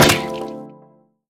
ruleset-select-fruits.wav